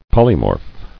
[pol·y·morph]